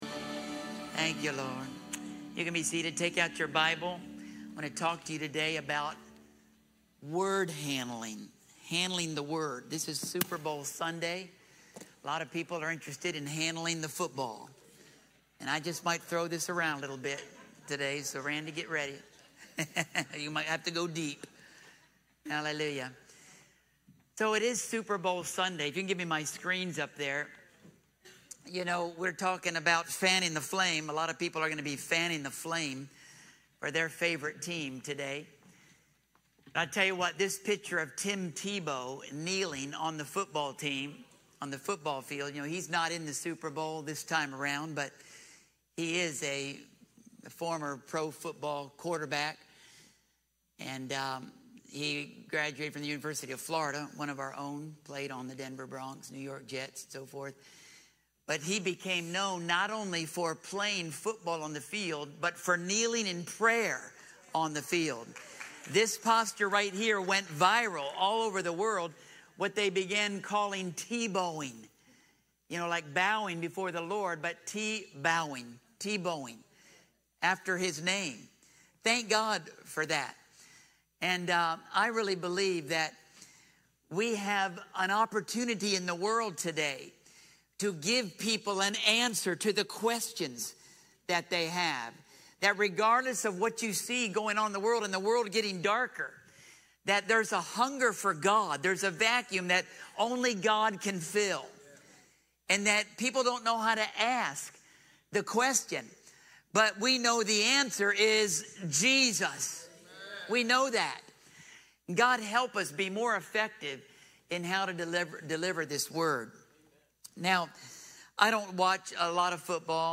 Sermons & Notes | Gospel Crusade Inc. | The Family Church at Christian Retreat | Christian Retreat Conference Center | Gospel Crusade Ministerial Association | Institute of Ministry